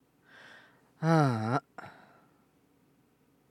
音量注意！
ただこのため息はやり方が重要で、上記のようにただただでかい声で「あぁ～～あ！」みたいな感じの嫌味なため息をしてしまうと結構逆効果になったりするので、しょんぼり落ち込んだ感じでやるのがベターです。